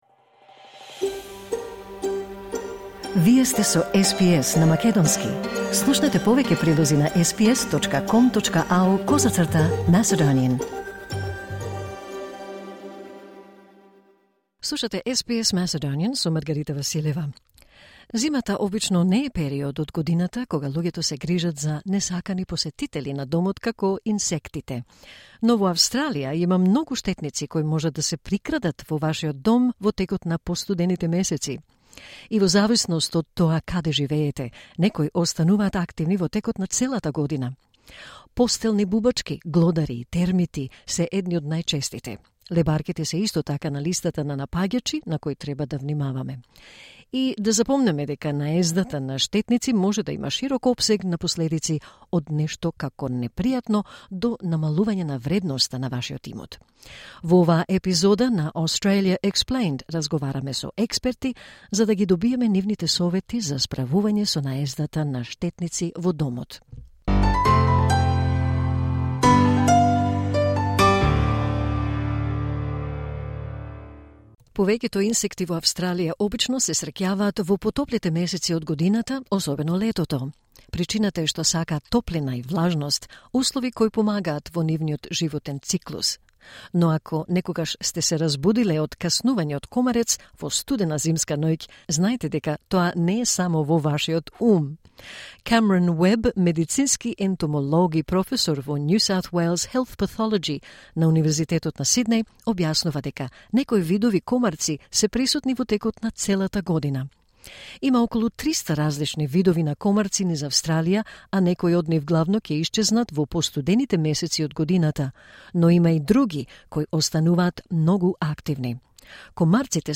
Hаездата на штетни инсекти може да има широк опсег на последици, од нешто непријатно до намалување на вредноста на вашиот имот. Во оваа епизода на "Australia Explained", разговараме со експерти за да ги добиеме нивните совети за справување со штетниците во домот.